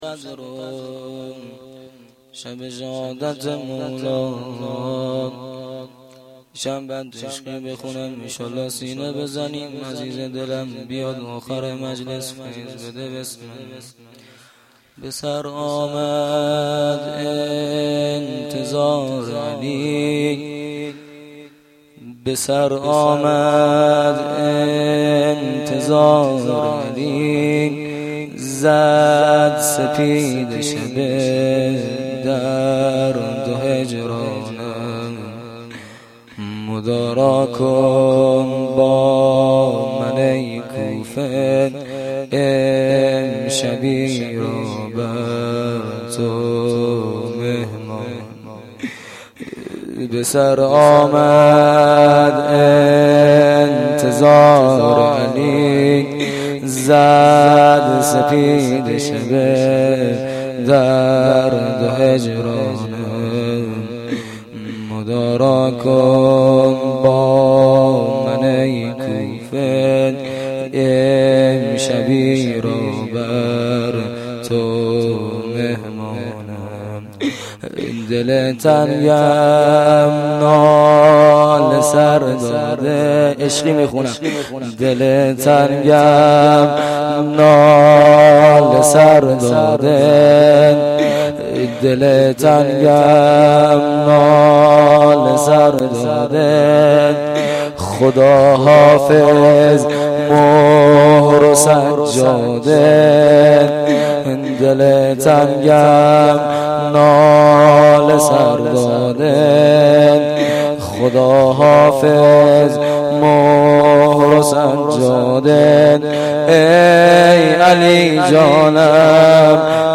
روضه
roze.mp3